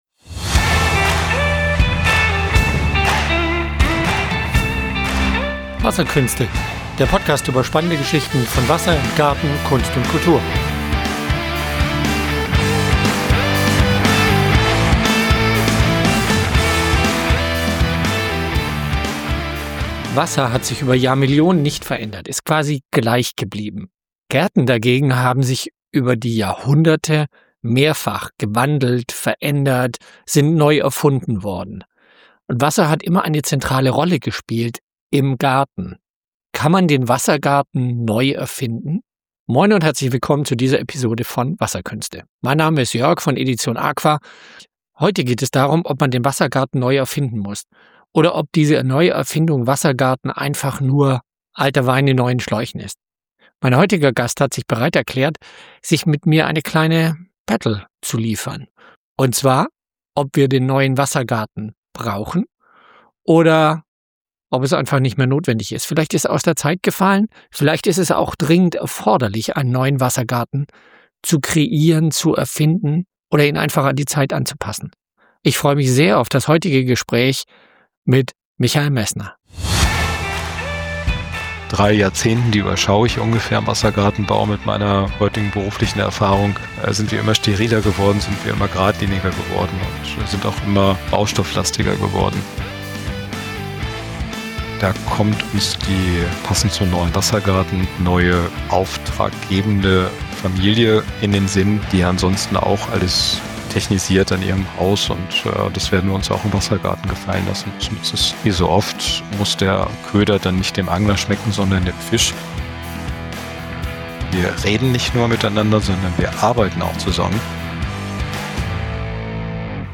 Zwei Experten für Wassergärten hinterfragen die zunehmend sterileren und technisierten Wassergärten.